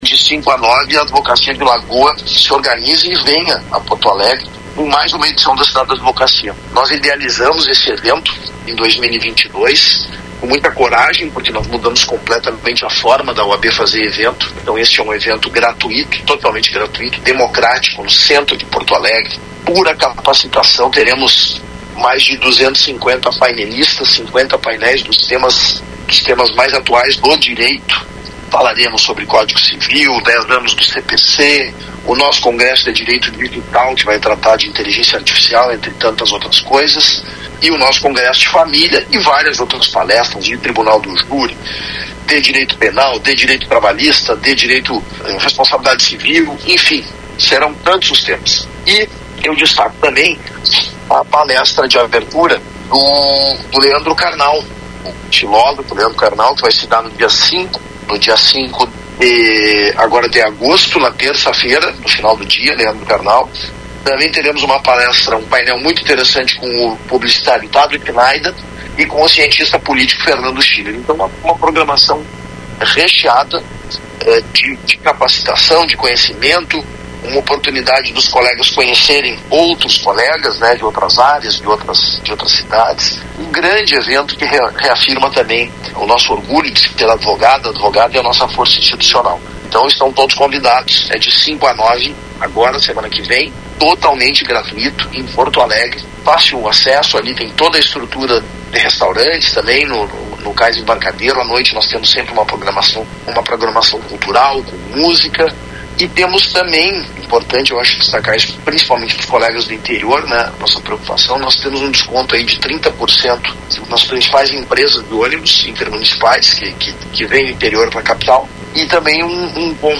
foi ouvido pela Rádio Lagoa FM sobre esse evento.